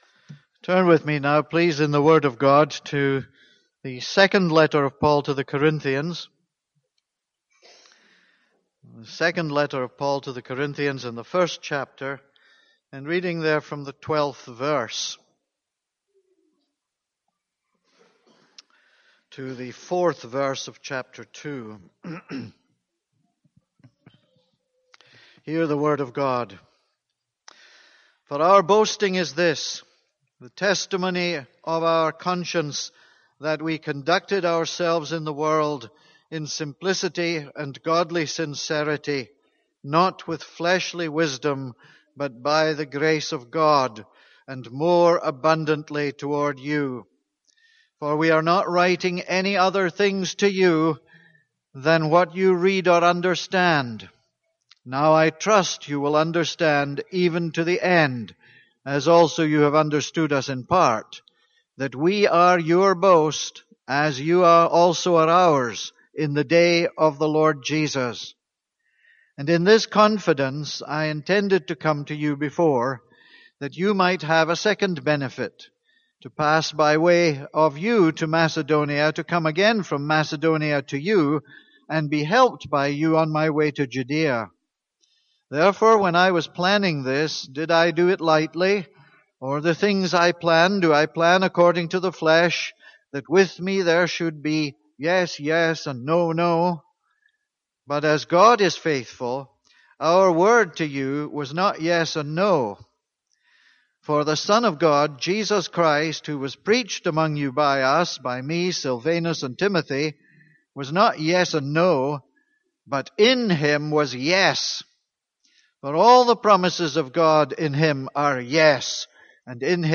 This is a sermon on 2 Corinthians 1:12-2:4.